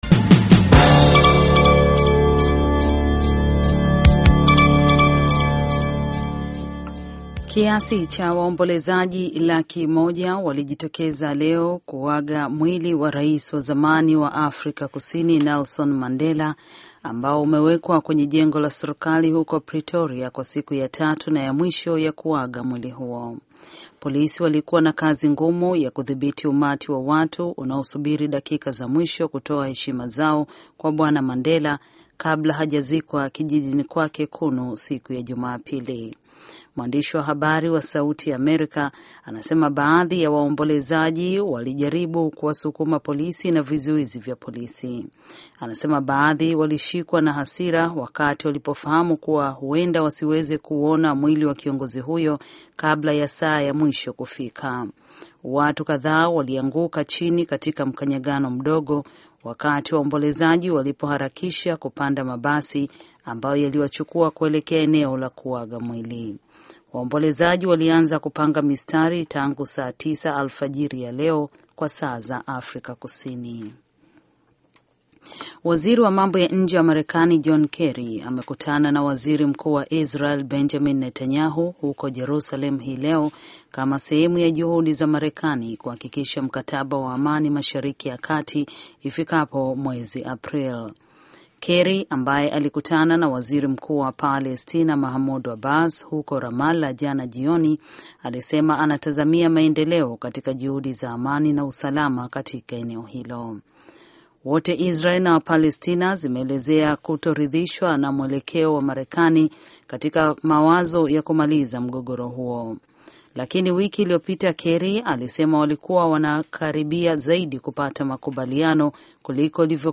Taarifa ya Habari VOA Swahili - 7:18